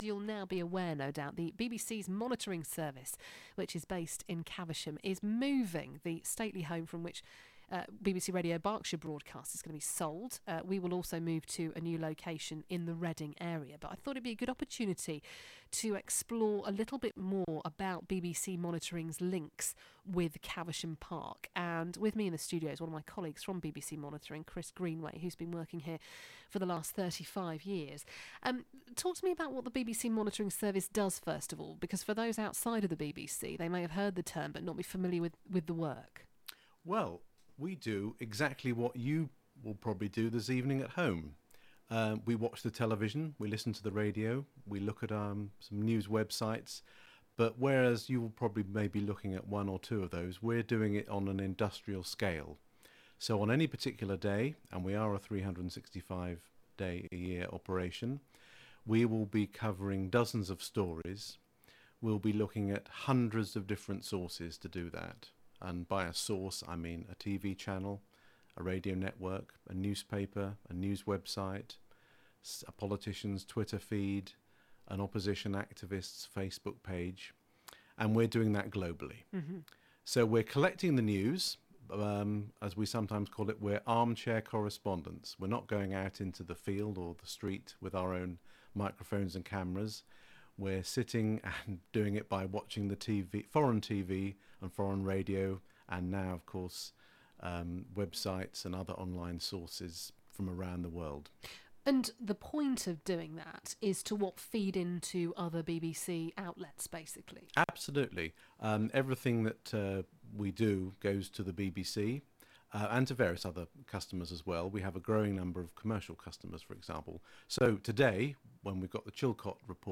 on the Anne Diamond show on BBC Radio Berkshire